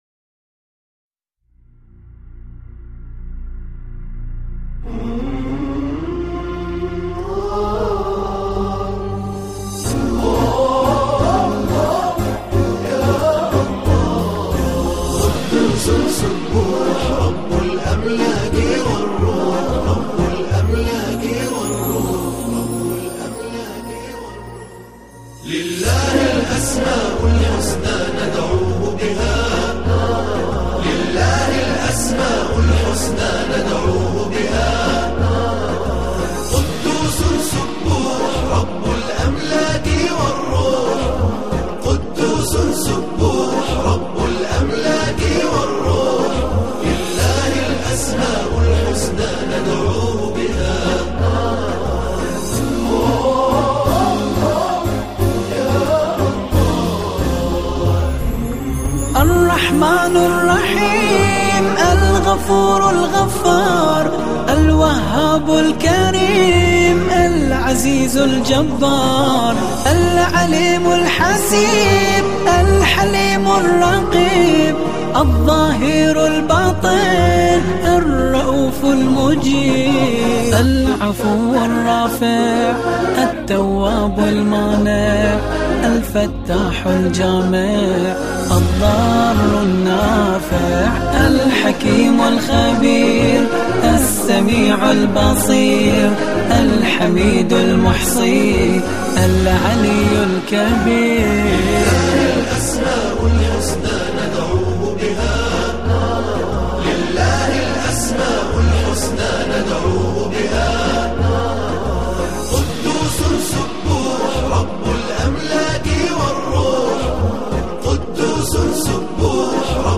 با نواهای دلنشین